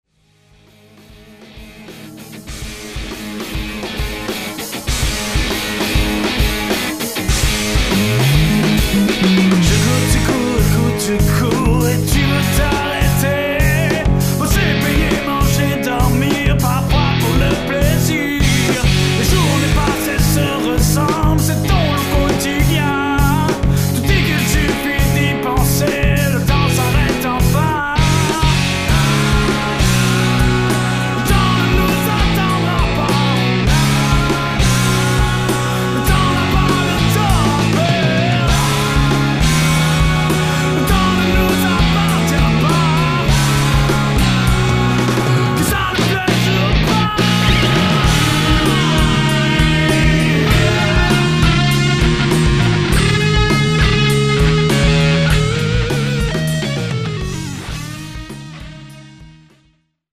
bass
drums
guitar/lead vocal
Demo Songs